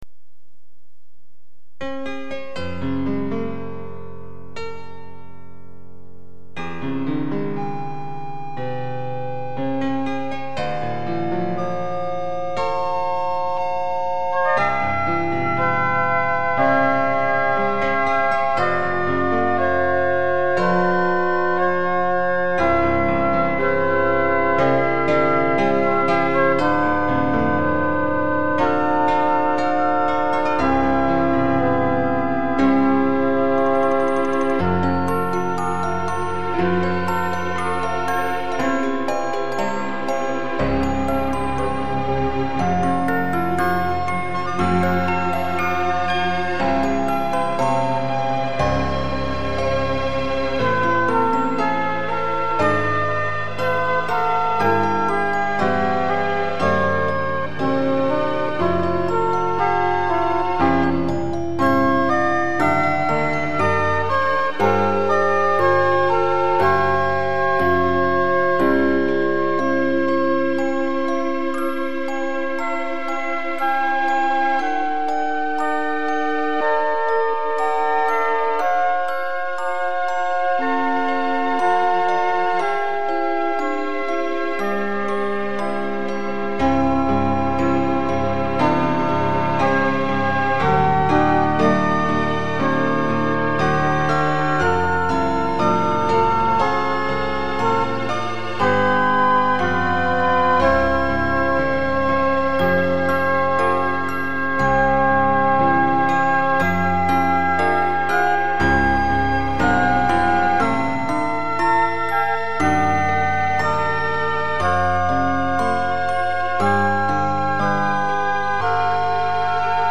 あのときのコーヒーの香りも 安らかなホットチョコの甘さもないけれど 時の流れの、その先にある、 あなたの姿を、わたしは捉えた 解説 半ピッチほどずれていますが、故意です。
00:56 分類 インストゥルメンタル